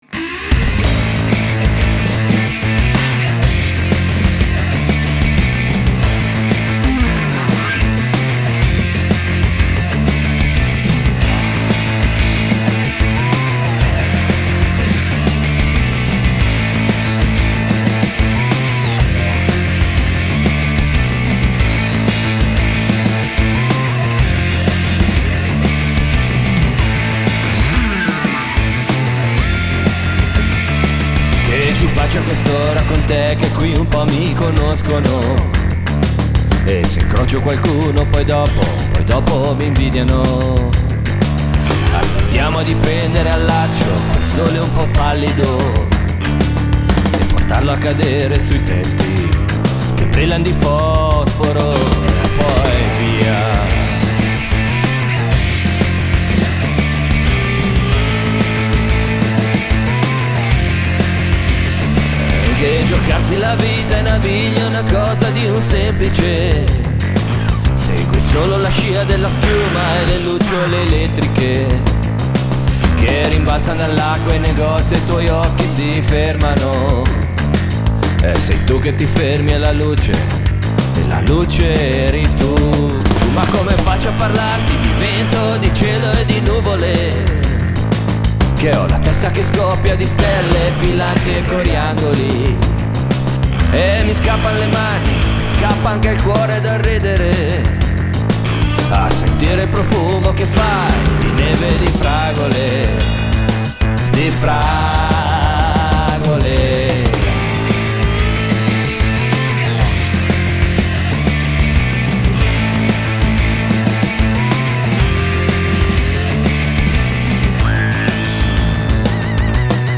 No Real Instruments Involved
basso fretless usato chitarristicamente